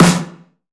• Short Reverb Snare Sound F Key 322.wav
Royality free snare drum tuned to the F note. Loudest frequency: 1481Hz
short-reverb-snare-sound-f-key-322-aCr.wav